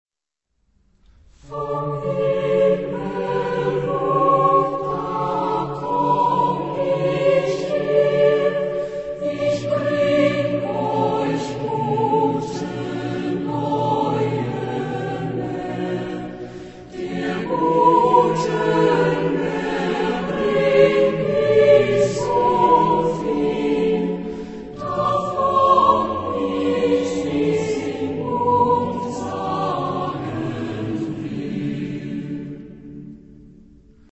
Genre-Style-Form: Motet ; Sacred ; Renaissance
Type of Choir: SSATB  (4 mixed voices )
Soloist(s): Soprano (1)  (1 soloist(s))
Tonality: D major